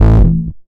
Double Bass Short (JW3).wav